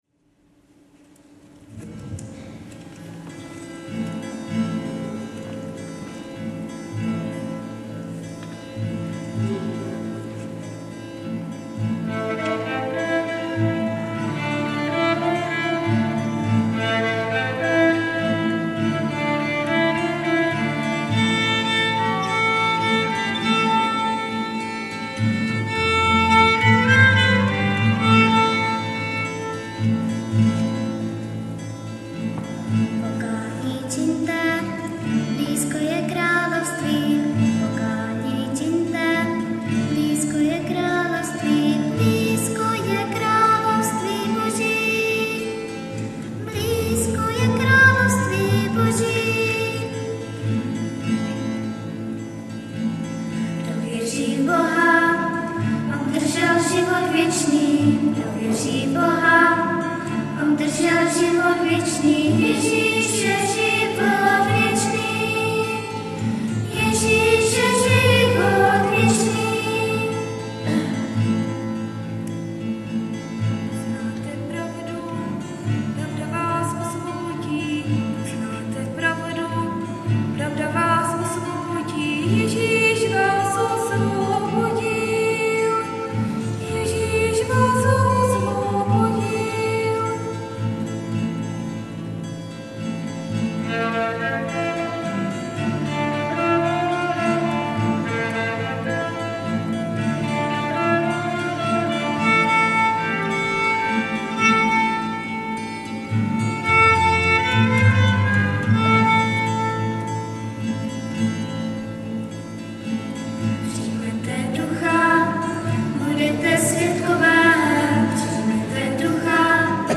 ADVENTNÍ KONCERT
Březová … kostel sv. Cyrila a Metoděje ... neděla 9.12.2007
POKÁNÍ ČIŇTE... březovská schola
... pro přehrátí klepni na názvy písniček...bohužel ně skomírala baterka v MD ...